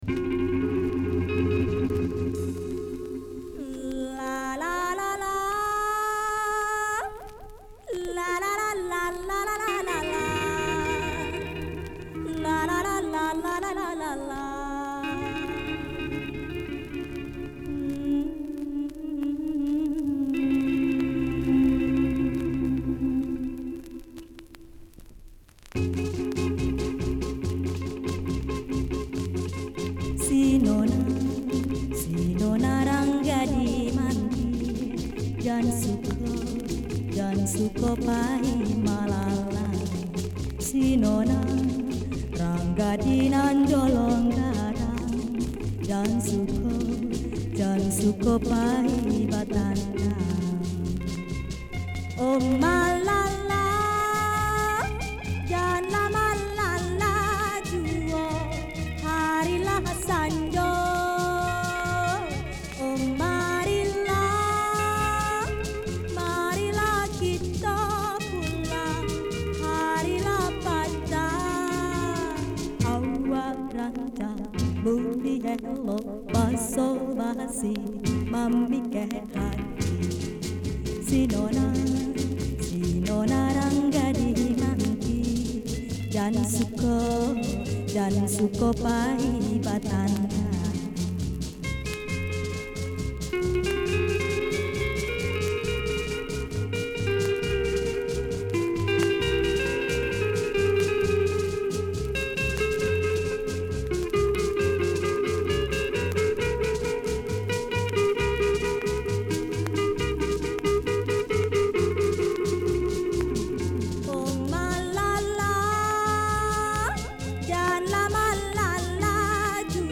En Indonésie aussi ils ont des danses de salon.